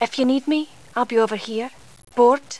Sound Bites
Here are a few .wav files of Annah speaking. Her voice is done by the talented Sheena Easton, and she has an awesome accent.